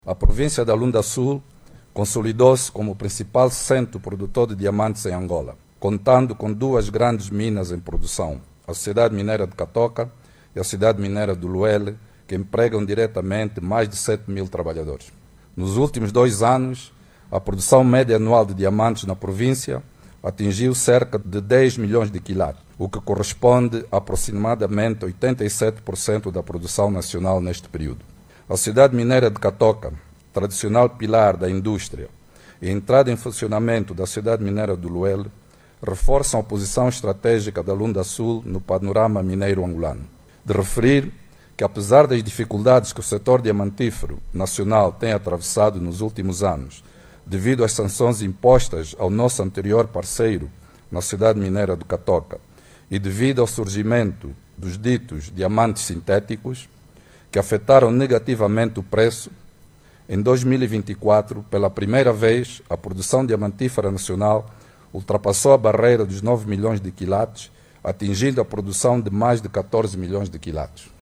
Diamantino de Azevedo, que falava momentos depois da inauguração do Instituto Politécnico Lueji A’Nkonde, na cidade de Saurimo, revelou que as empresas do sector diamantífero vão continuar a desempenhar o seu papel social para melhorar as condições de vida das comunidades.